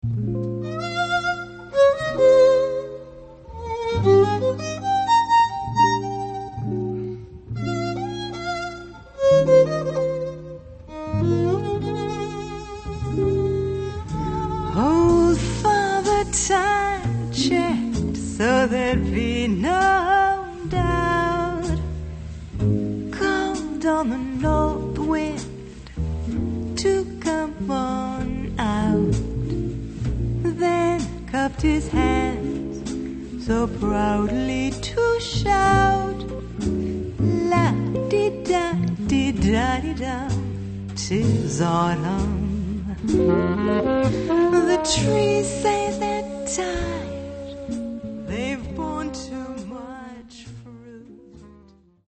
Jazz Vocalist
vocals
bass (1, 2, 8, 9, 11, 16)
drums (1, 2, 4, 6, 7, 8, 9, 10, 11, 12. 15, 17)
acoustic guitar, electric guitar (1, 2, 8, 9, 11)
violin (2, 8, 9, 11)
flute, tenor saxophone, clarinet (1, 2, 8, 9, 11)